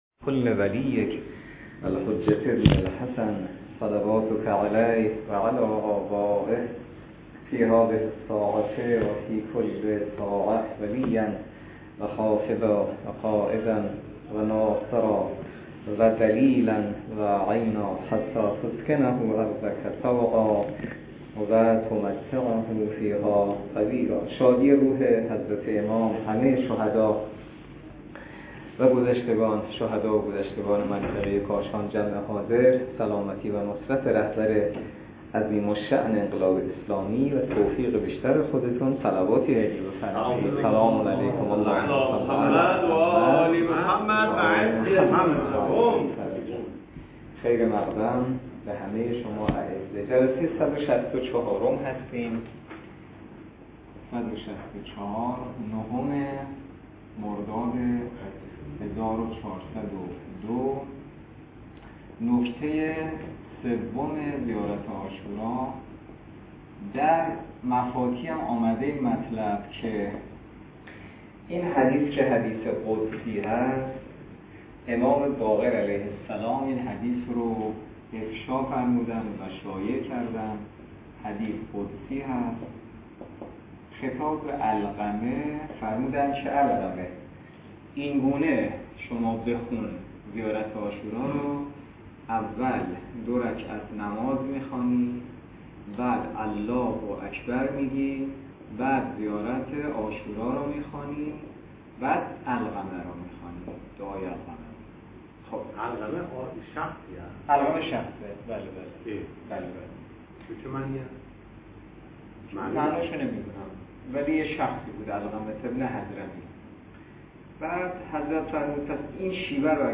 درس فقه الاجاره نماینده مقام معظم رهبری در منطقه و امام جمعه کاشان - جلسه صد و شصت و چهار .